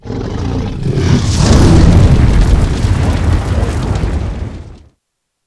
FireBreath2.wav